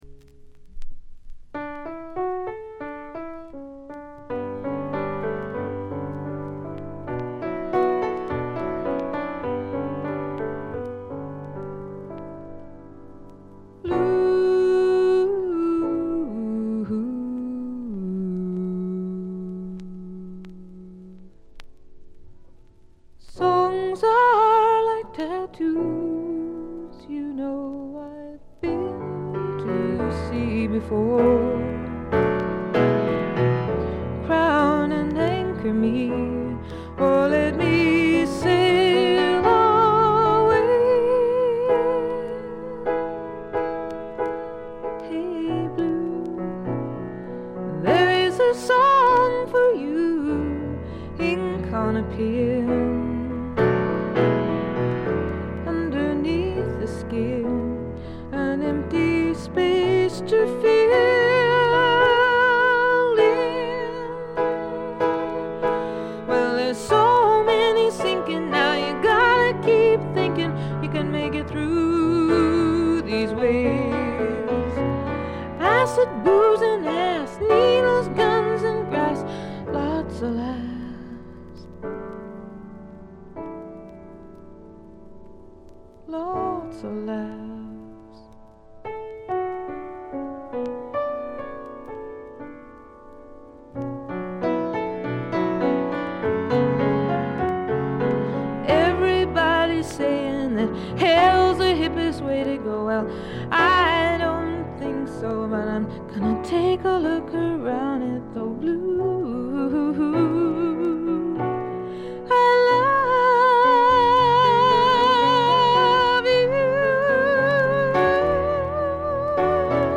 全体にバックグラウンドノイズ。細かなチリプチ多めですが、鑑賞を妨げるほどのノイズはないと思います。
ほとんど弾き語りに近いごくシンプルな演奏が染みます。
試聴曲は現品からの取り込み音源です。